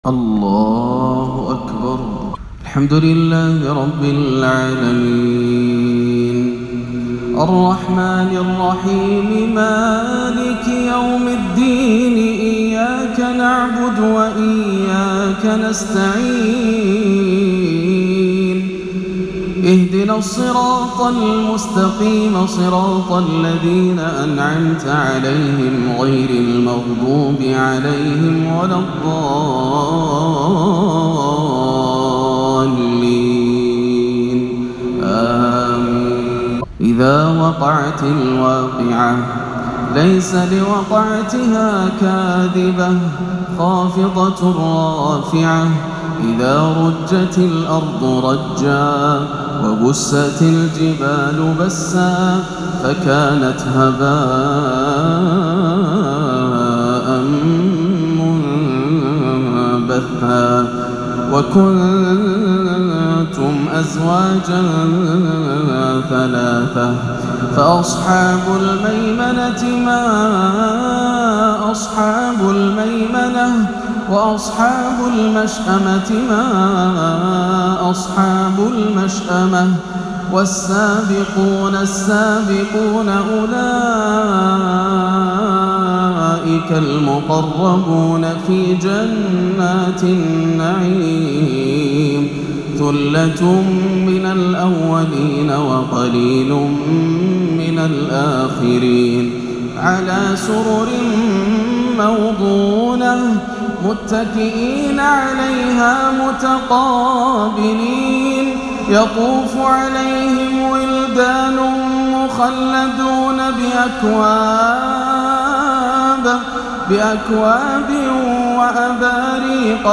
(فلولا إذا بلغت الحلقوم) تلاوة خاشعة نادرة لسورة الواقعة كاملة- عشاء الأحد 22-7 > عام 1439 > الفروض - تلاوات ياسر الدوسري